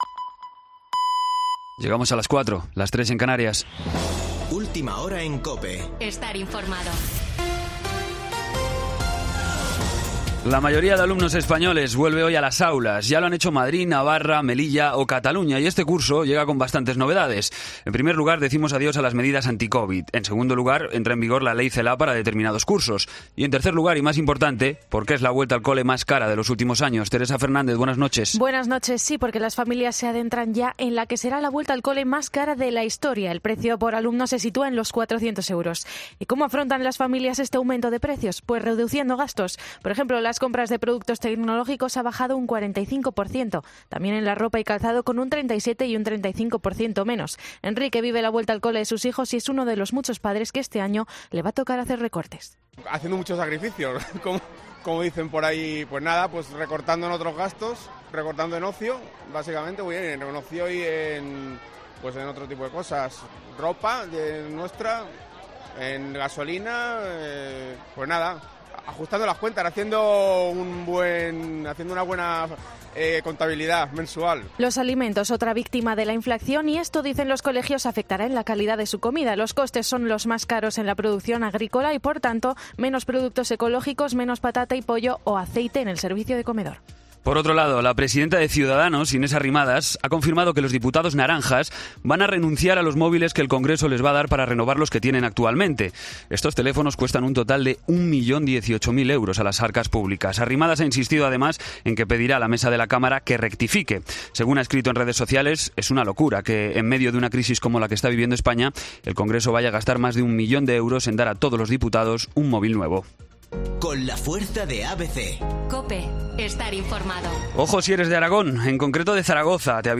Boletín de noticias COPE del 8 de septiembre a las 04:00 horas